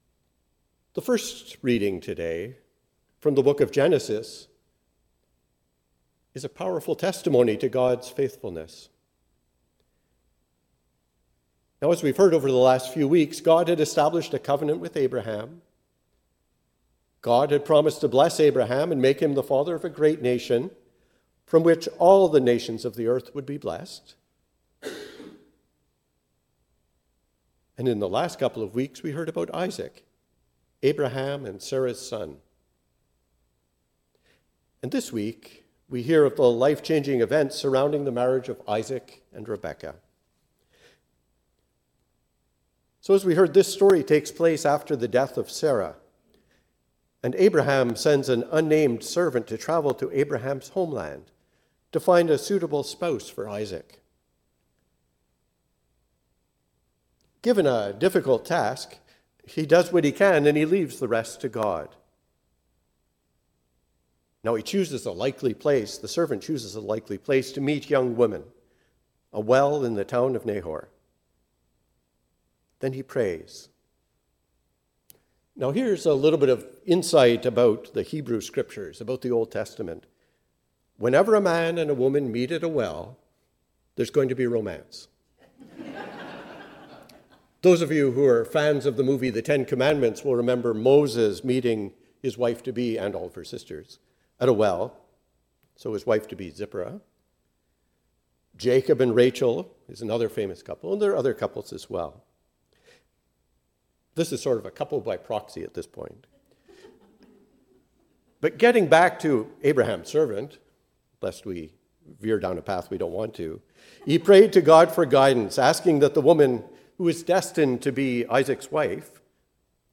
A Sermon for the Sixth Sunday after Pentecost